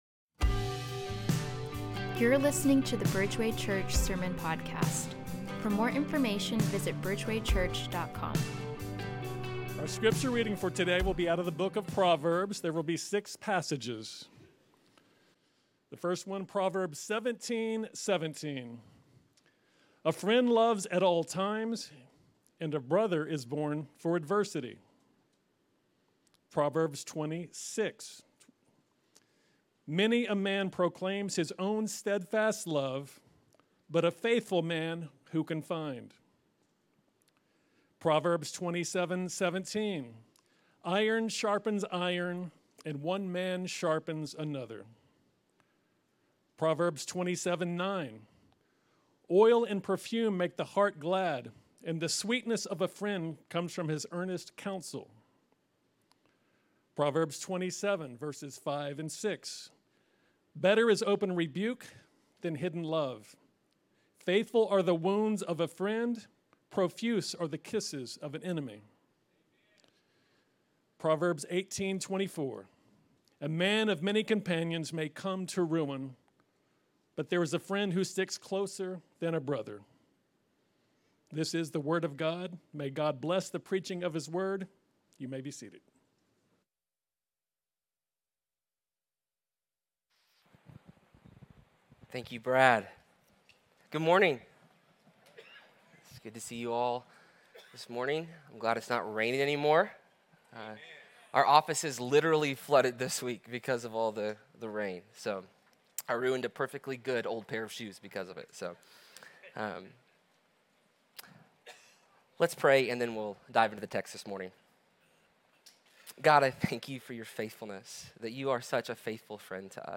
Bridgeway Church Sermons
may-4-2025-sermon-audio.m4a